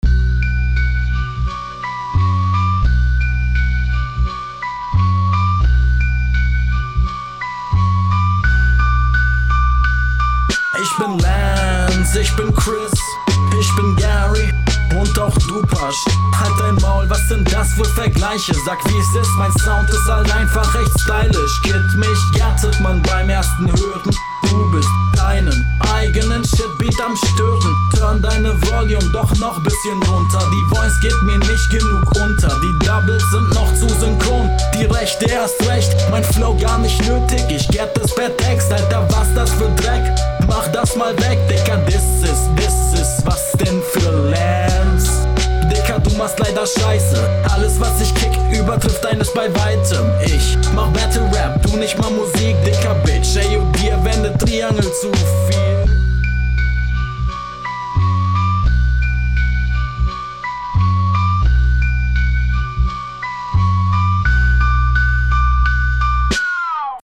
In allen Ansätzen besser, kommst dope auf den Beat, bringst gute Lines.